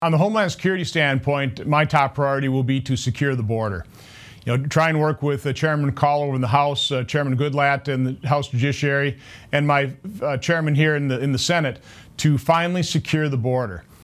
Senator Johnson gave these answers during an interview on Wednesday, Nov. 12, with WBAY-TV.